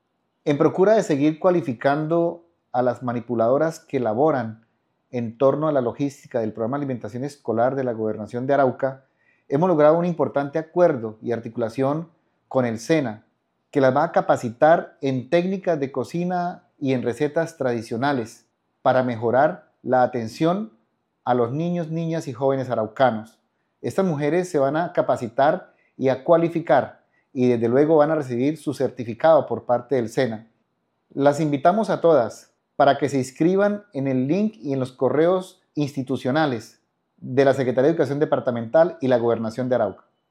por Secretario de Educación Departamental de Arauca
Secretario-de-educacion.mp3